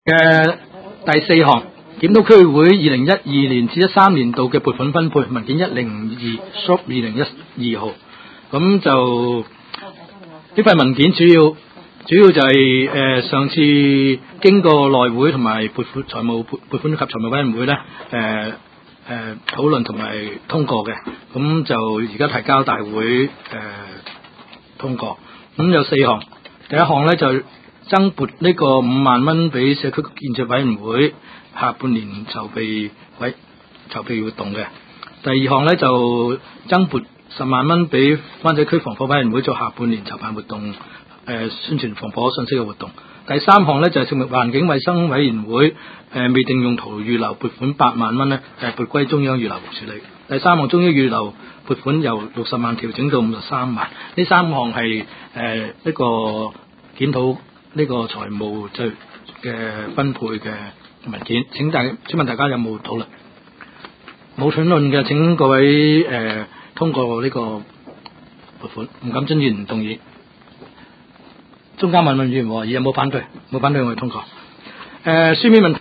区议会大会的录音记录
湾仔区议会第七次会议